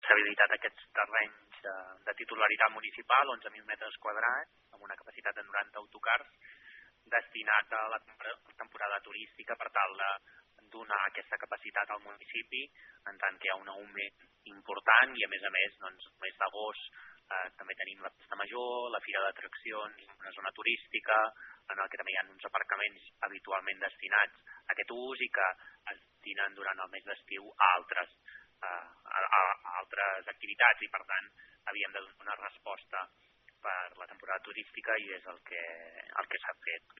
N’ha parlat, en declaracions a aquesta emissora, el regidor d’Urbanisme de l’Ajuntament de Malgrat de Mar, Jofre Serret.